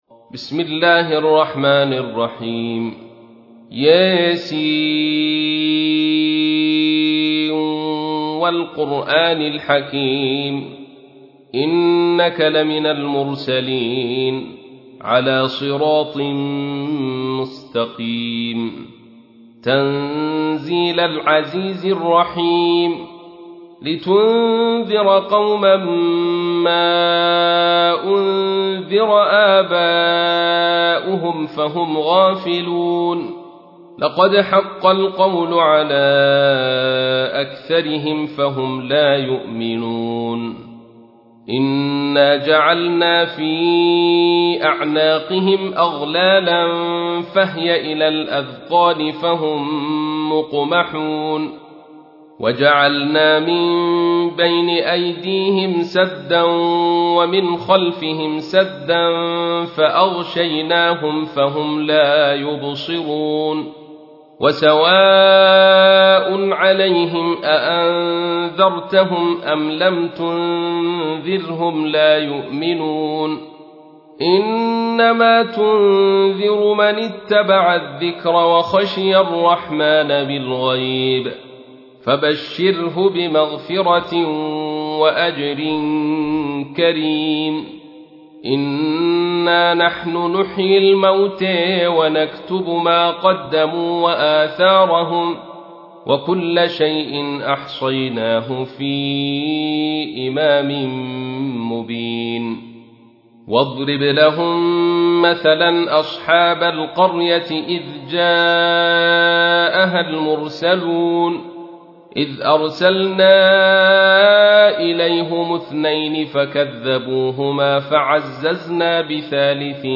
تحميل : 36. سورة يس / القارئ عبد الرشيد صوفي / القرآن الكريم / موقع يا حسين